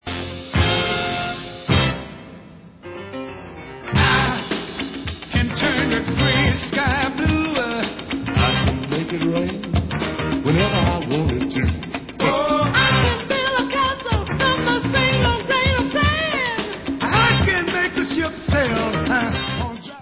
sledovat novinky v oddělení Pop/Rhytm & Blues